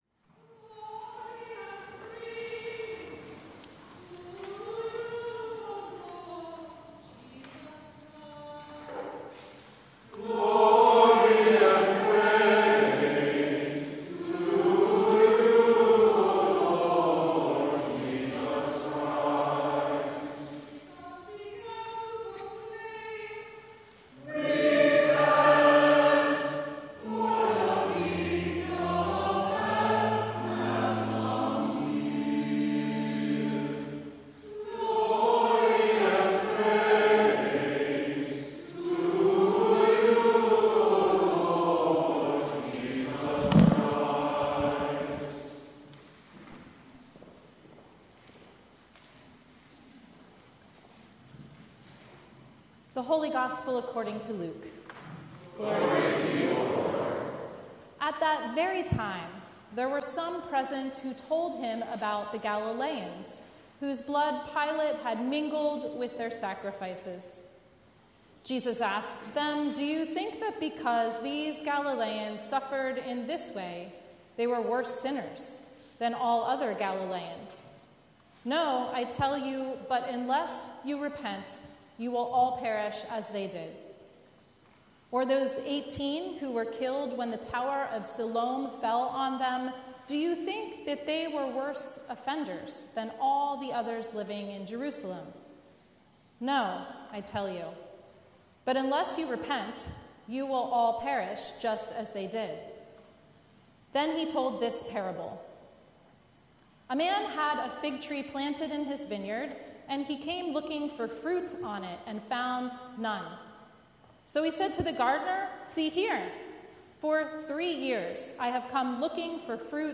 Sermon from the Third Sunday in Lent March 23
Sermon Notes